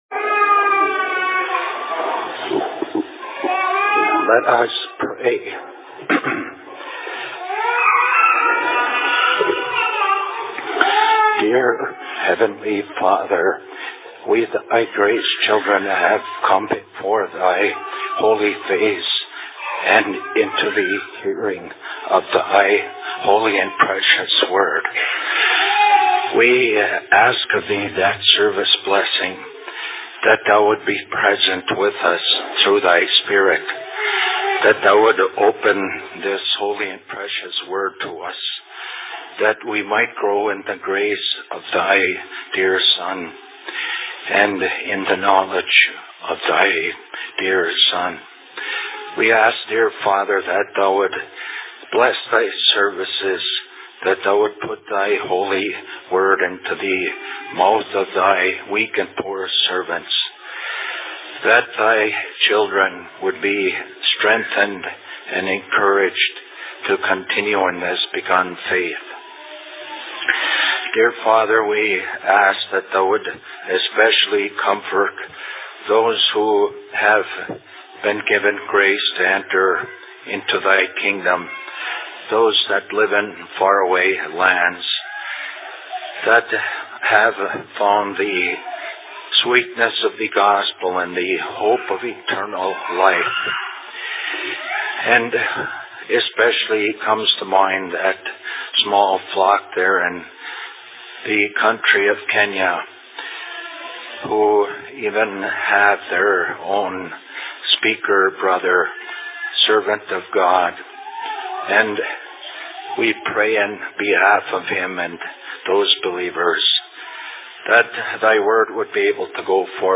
Sermon in Ishpeming 14.08.2005
Location: LLC Ishpeming